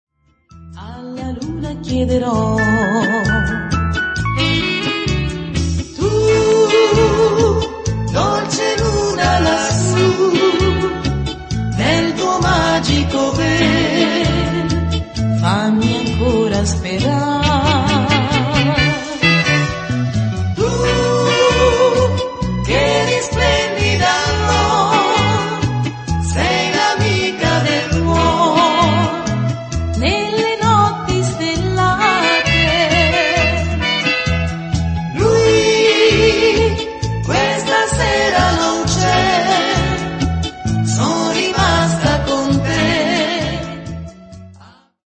valzer lento